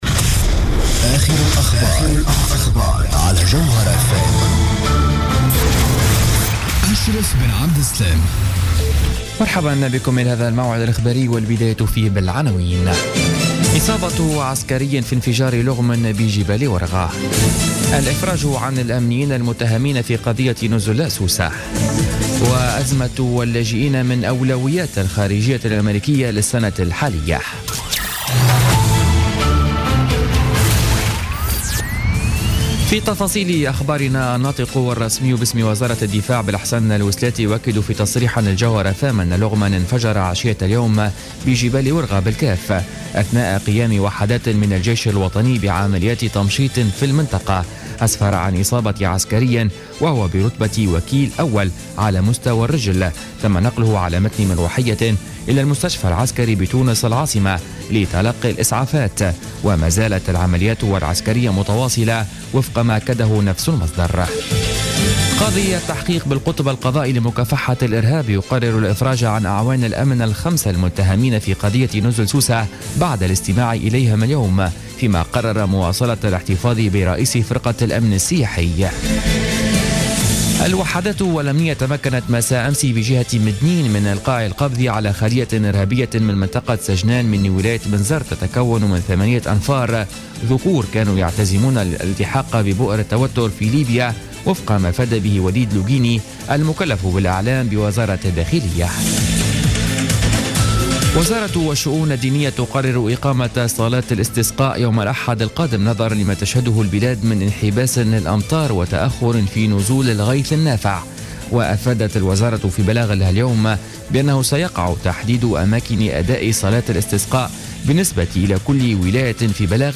نشرة أخبار السابعة مساء ليوم الاربعاء 13 جانفي 2016